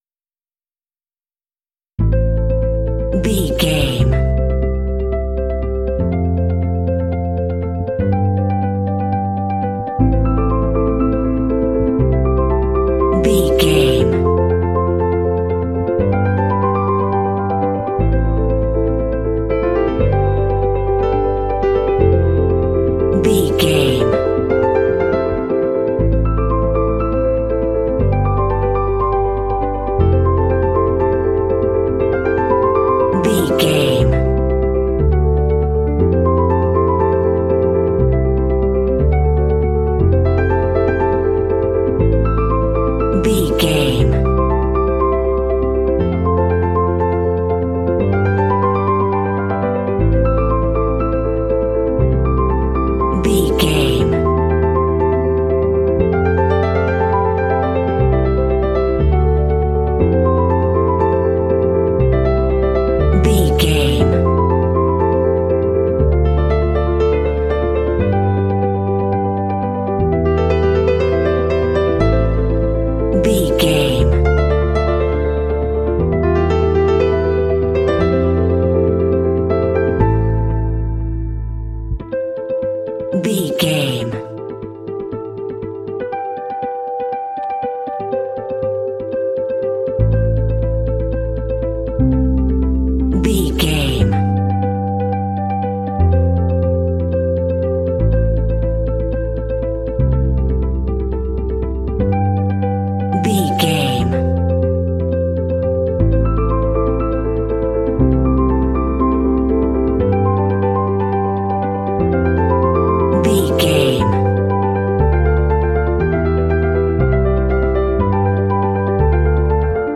Aeolian/Minor
melancholy
contemplative
serene
electric guitar
bass guitar
drums
piano
ambient
contemporary underscore